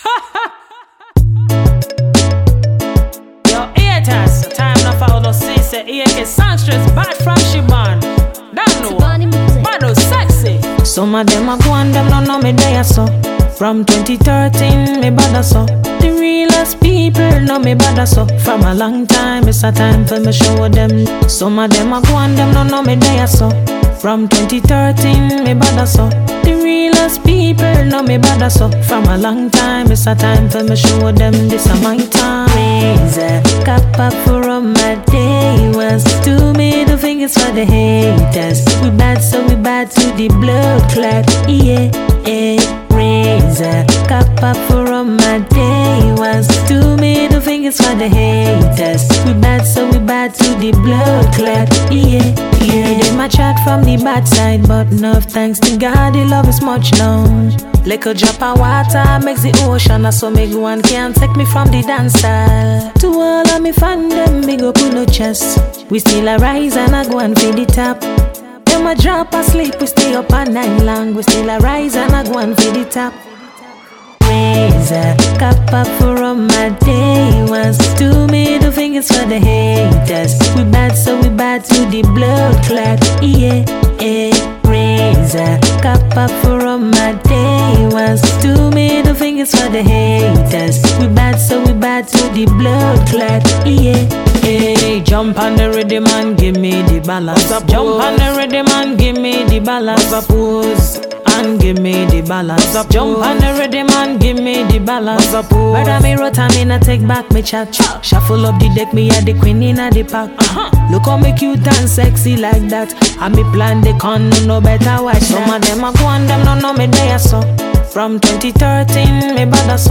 dancehall tune